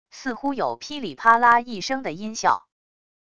似乎有噼里啪啦一声的音效wav音频